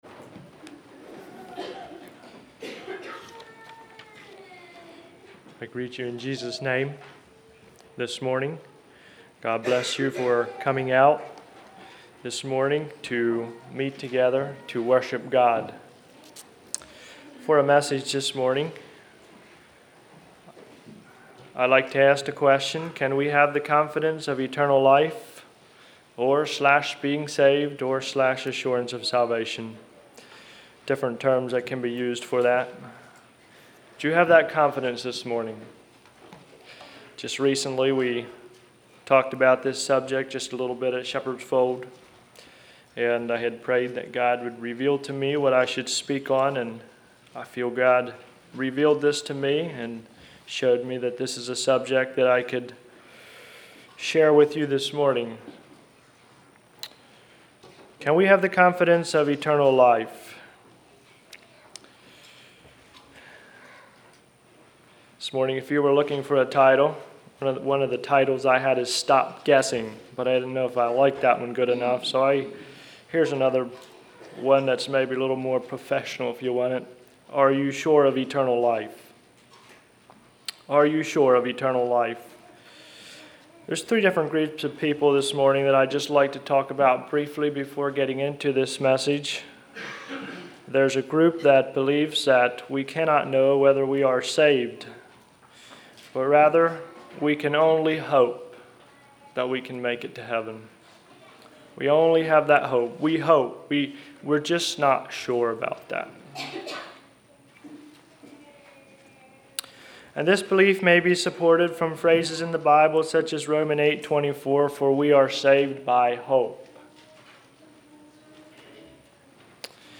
2018 Sermon ID